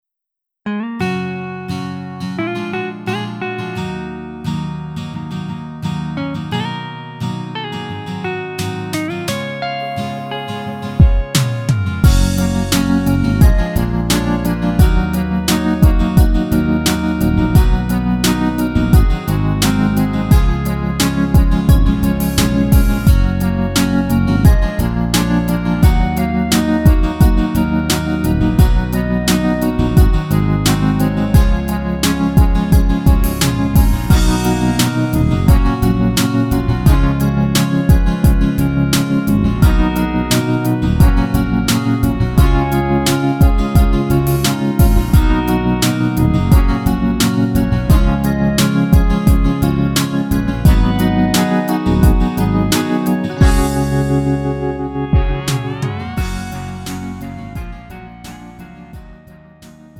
음정 -1키 2:30
장르 가요 구분 Lite MR